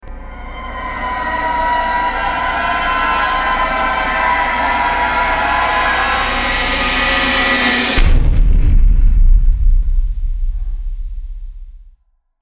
دانلود آهنگ ترور از افکت صوتی طبیعت و محیط
جلوه های صوتی
برچسب: دانلود آهنگ های افکت صوتی طبیعت و محیط دانلود آلبوم صداهای ترسناک از افکت صوتی طبیعت و محیط